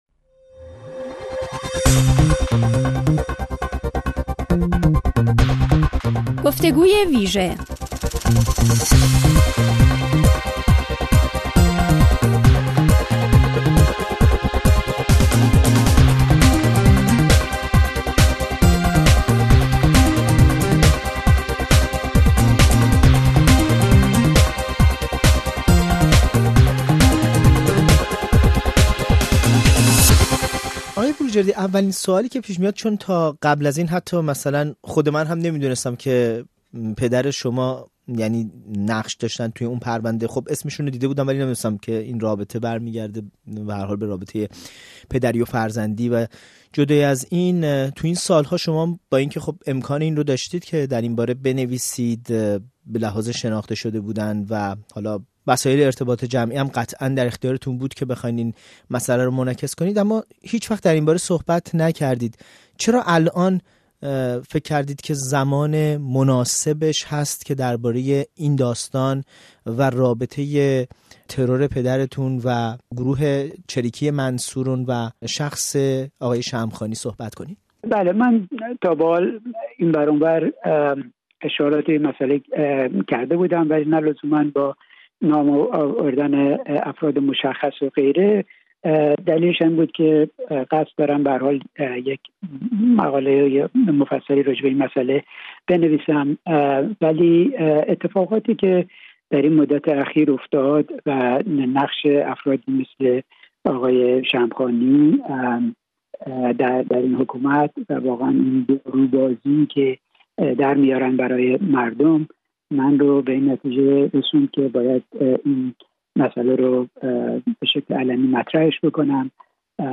در گفت‌وگو با برنامه خبری تحلیلی ایستگاه ۱۹